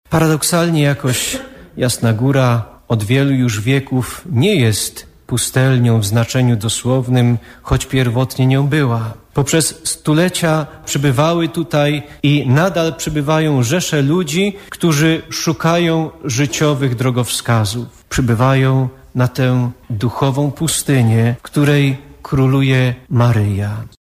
Na Jasnej Górze zakonnicy spotkali się na wspólnotowej mszy świętej, w czasie której odnowili swoje przymierze z Maryją.